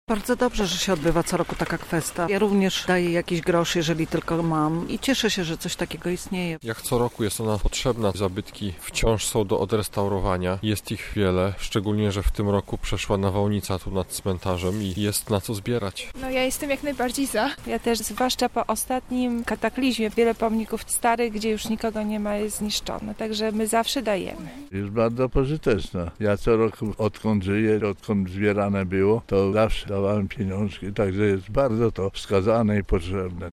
Zapytaliśmy Lublinian, co sądzą o akcji: